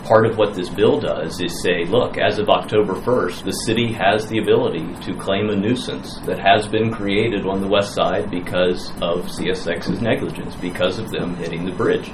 Former Allegany County commissioner Jake Shade presented the argument at Tuesday's city council meeting that Delegate Jason Buckel’s recently passed bill about damaging state infrastructure could be used against CSX for the Washington Street bridge issue.